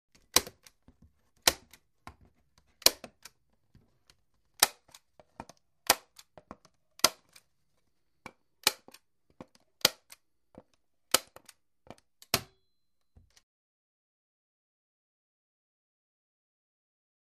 Staple Gun | Sneak On The Lot